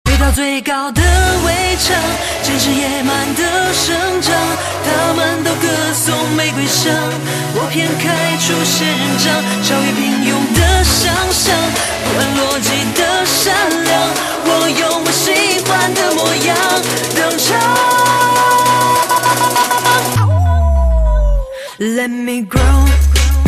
M4R铃声, MP3铃声, 华语歌曲 113 首发日期：2018-05-15 00:44 星期二